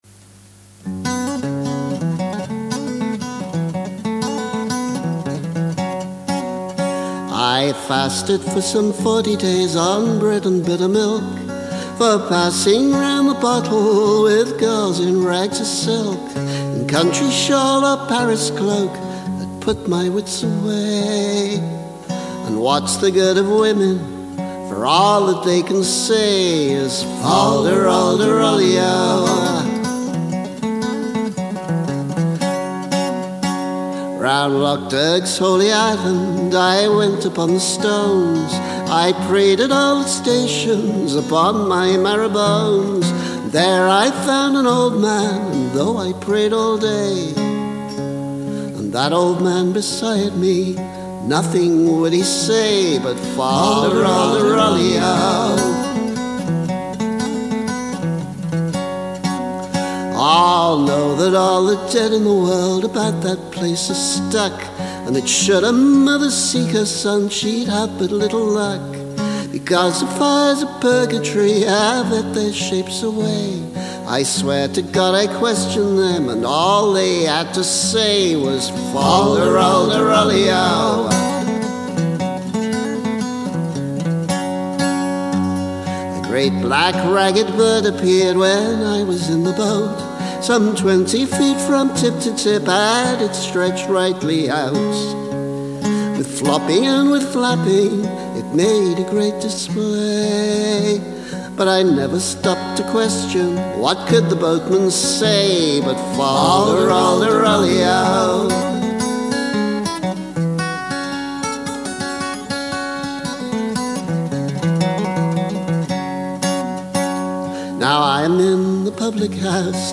The Pilgrim [demo]